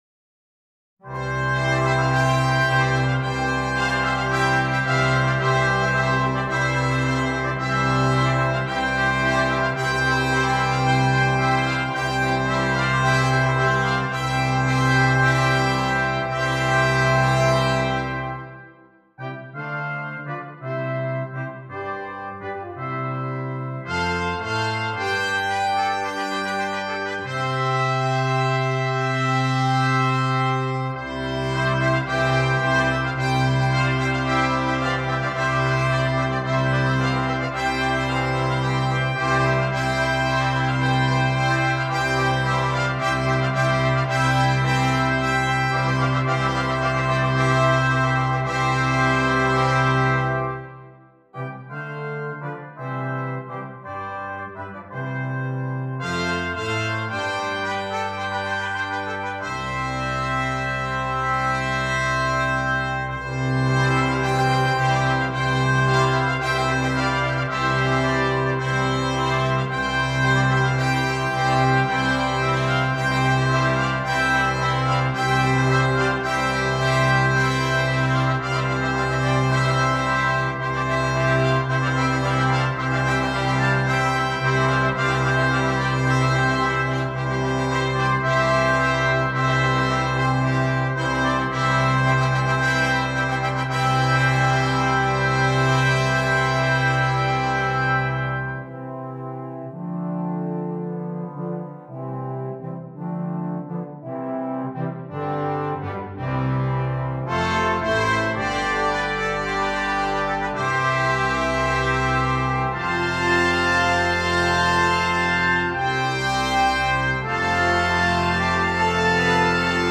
Double Brass Quintet
It has been arranged here for two antiphonal brass quintets.